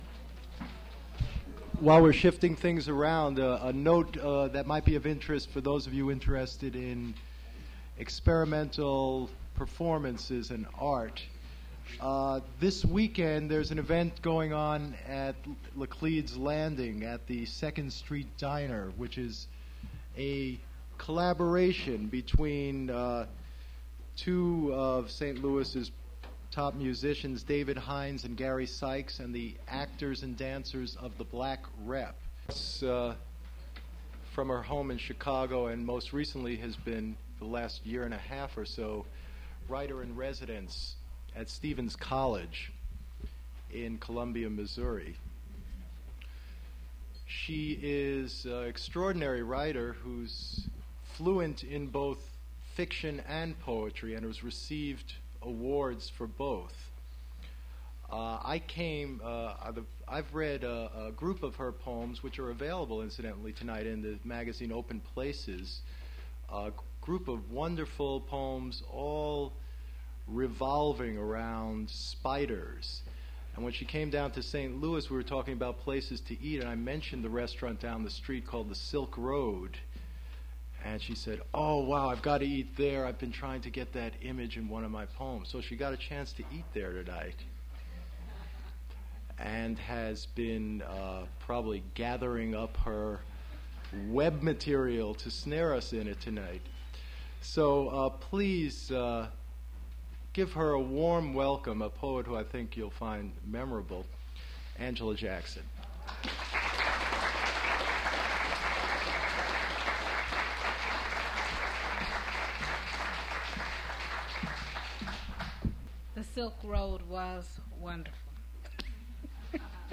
Poetry reading featuring Angela Jackson
• Angela Jackson poetry reading at Duff's Restaurant.
• mp3 edited access file was created from unedited access file which was sourced from preservation WAV file that was generated from original audio cassette.
• Recording is cut mid-sentence